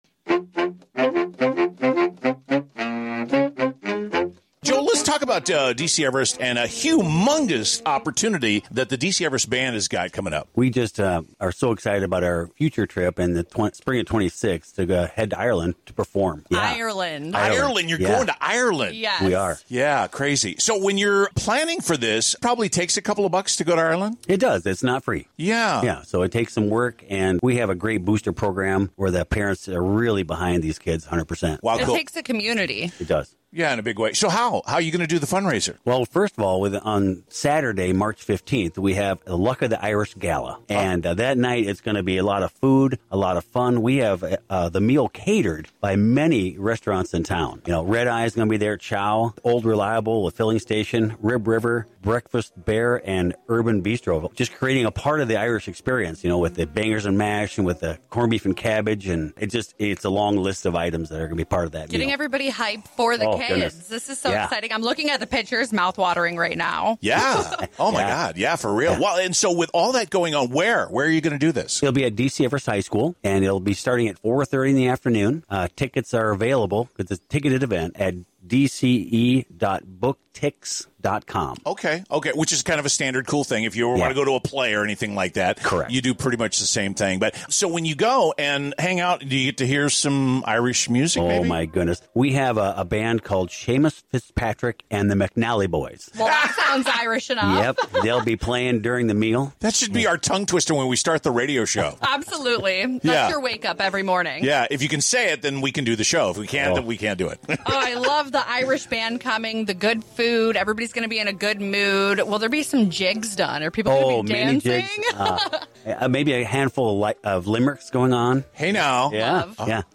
Interview and info all right here…..